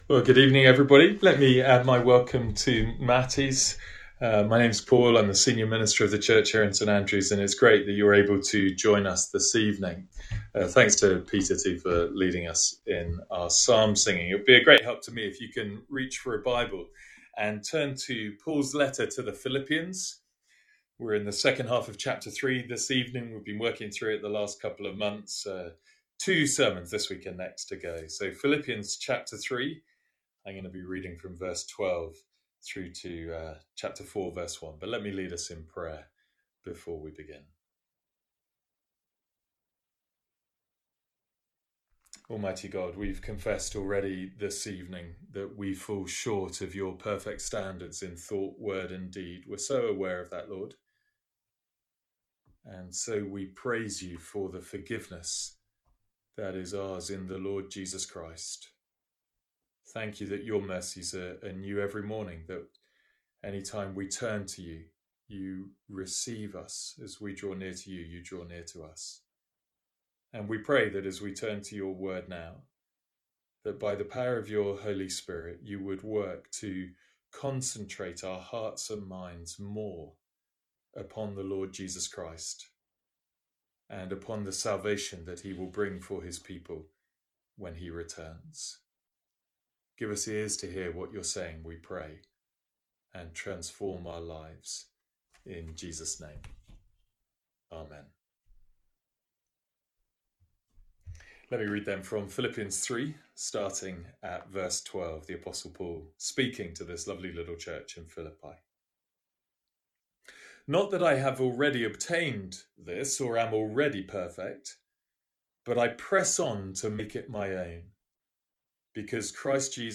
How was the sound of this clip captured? From our evening series in Philippians.